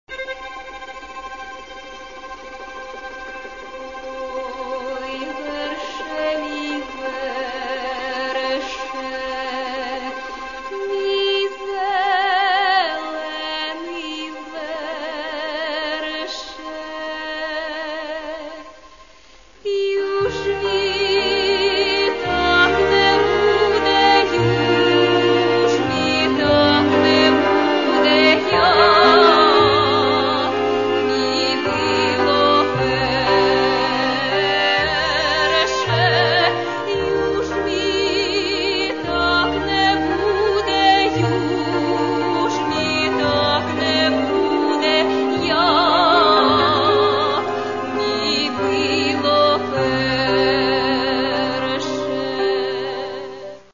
музика: Лемківська народна пісня